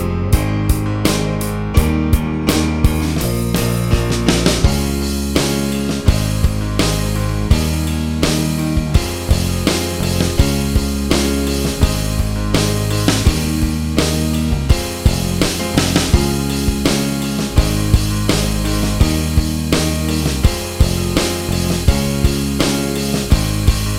Minus Guitars Rock 4:21 Buy £1.50